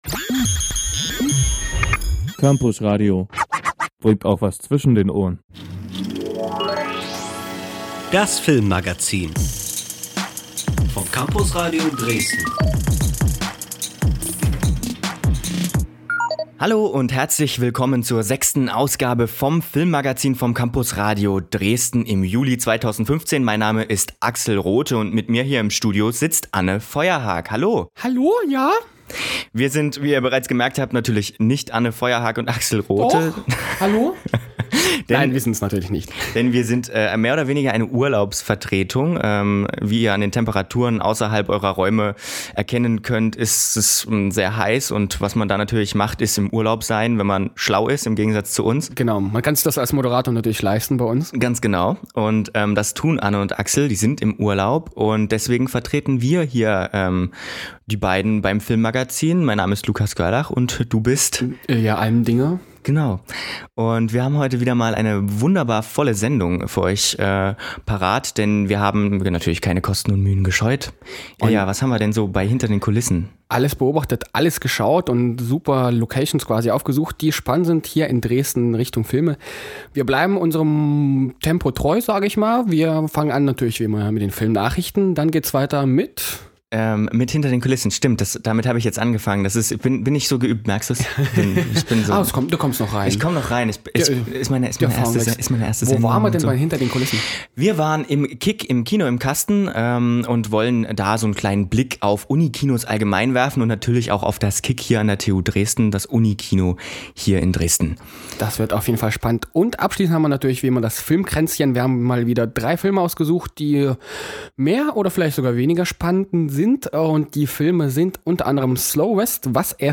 Wie sind sie entstanden? Wie arbeitet so ein Unikino? Wir waren für euch im Kino im Kasten zu Besuch und haben eine kleine Reportage darüber zusammengeschnitten.